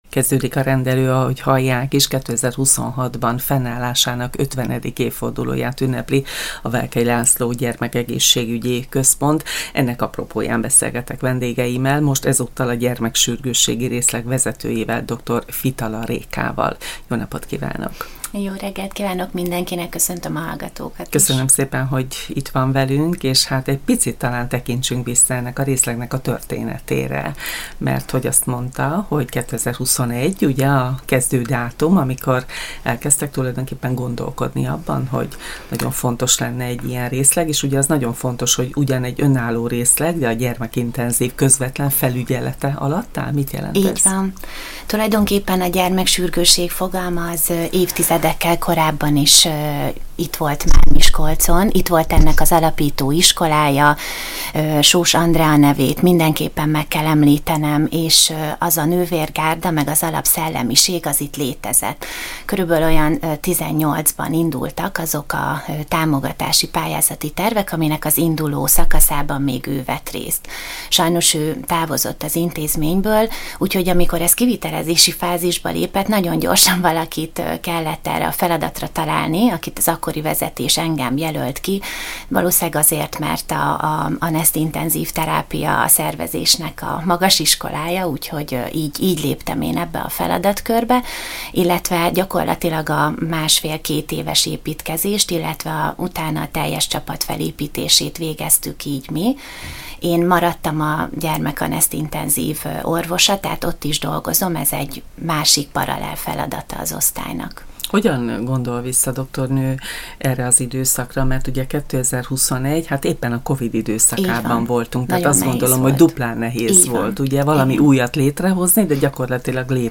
GYEK 50 - A stúdióban